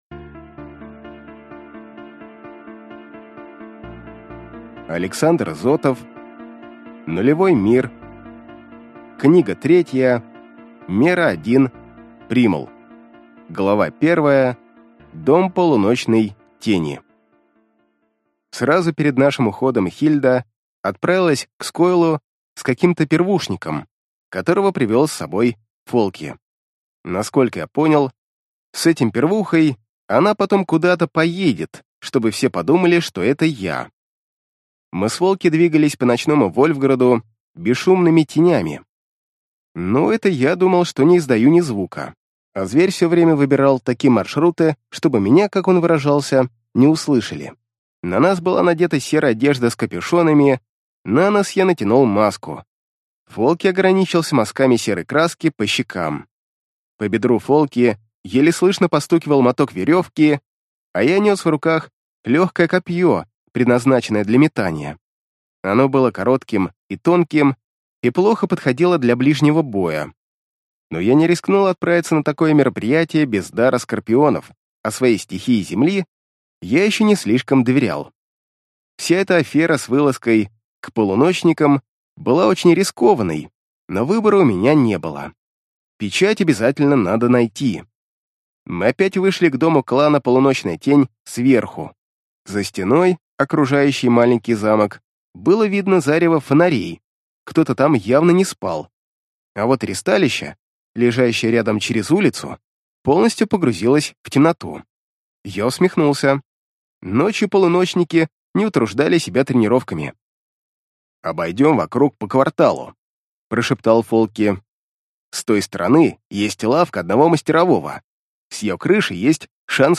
Аудиокнига Нулевой мир. Книга 3. Мера один: Примал | Библиотека аудиокниг